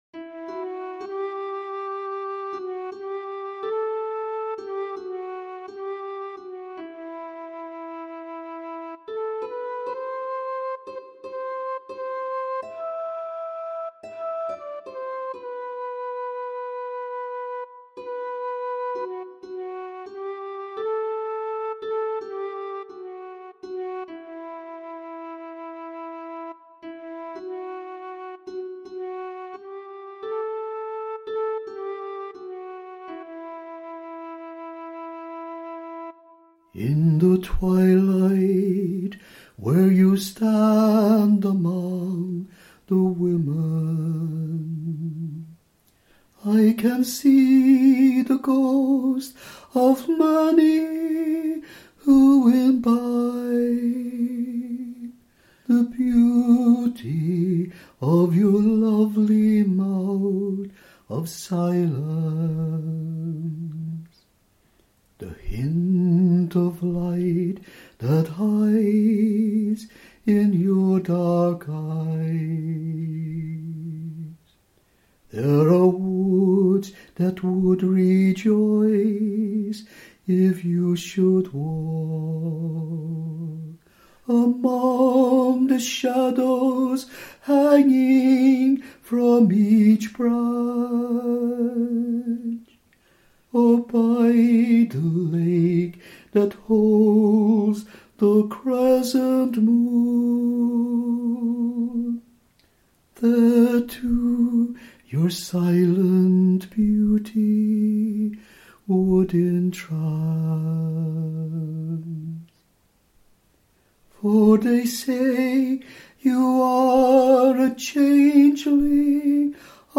(A song for a harp and a sweet voice, After F.R. Higgins)